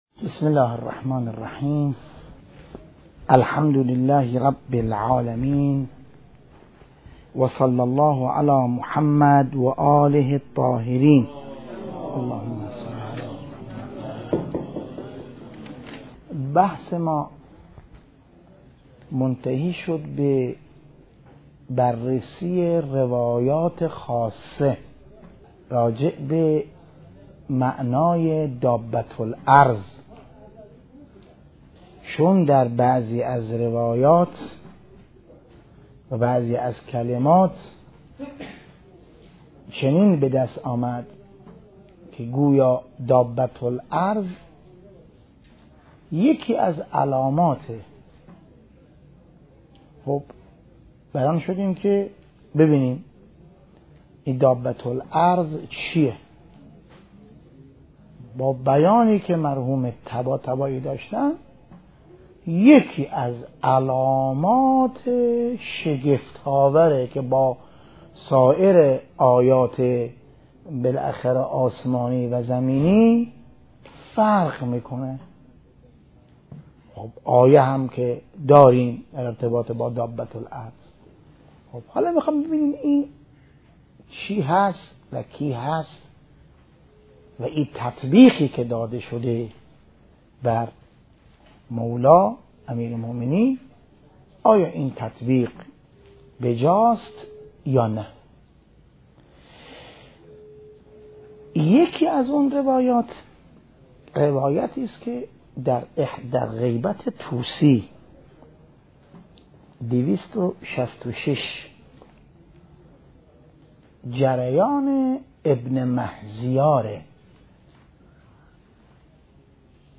بحث خارج مهدویت - روایات دابه الارض ج ۳